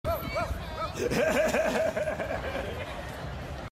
Seth Rogen Laugh while Running
seth-rogen-laugh-while-running.mp3